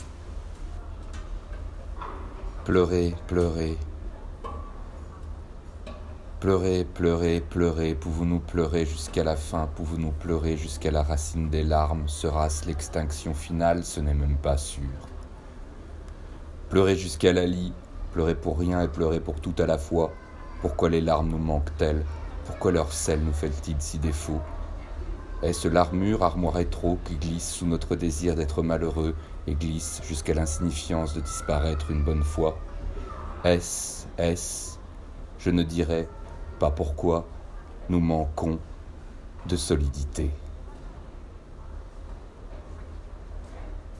Lecture du poème "Pleurer pleurer", le 1er mai 2018, hall de la Bibliothèque Royale / Black Diamond.